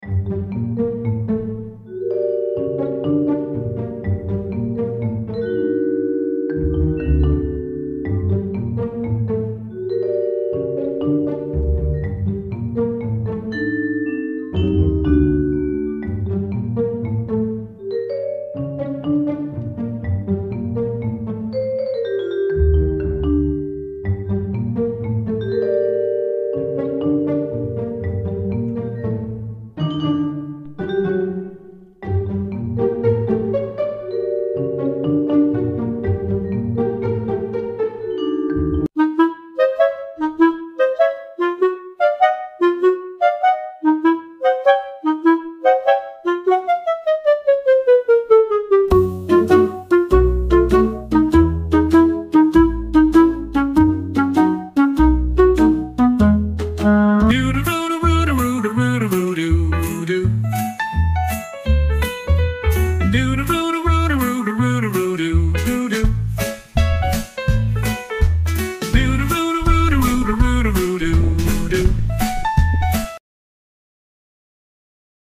This week's critter cam from the front yard